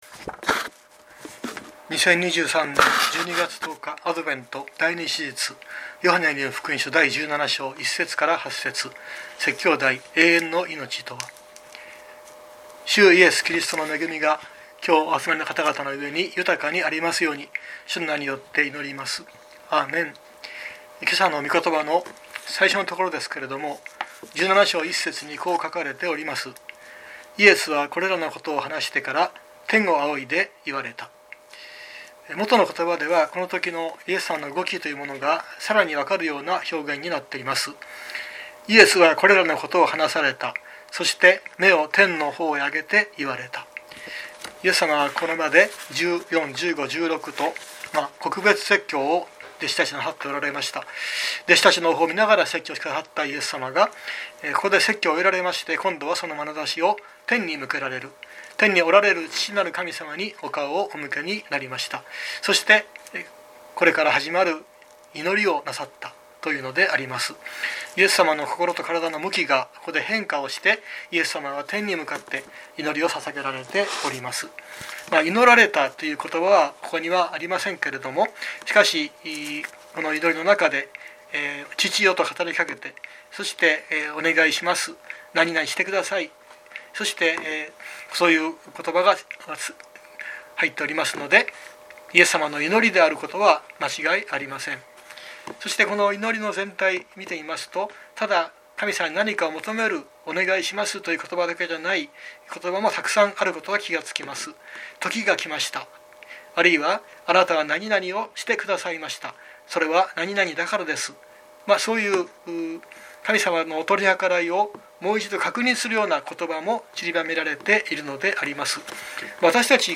2023年12月10日朝の礼拝「永遠の命とは？」熊本教会
熊本教会。説教アーカイブ。